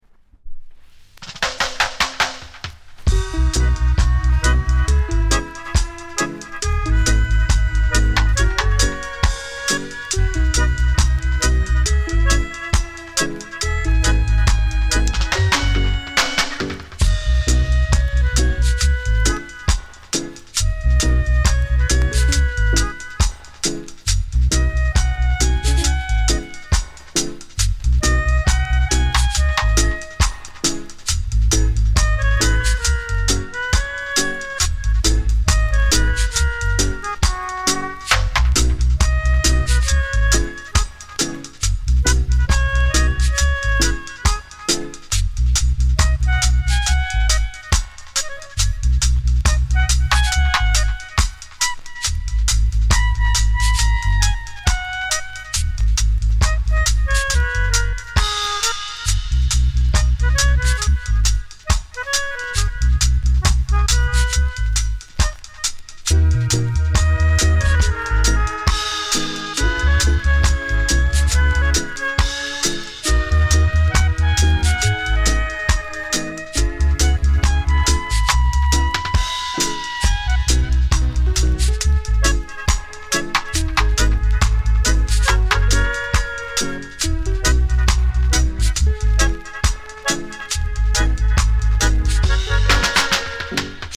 概ね美盤ですが、両面 音に影響ない 軽いセンターずれ。